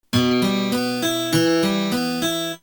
Способы аккомпанимента перебором
Em (2/4)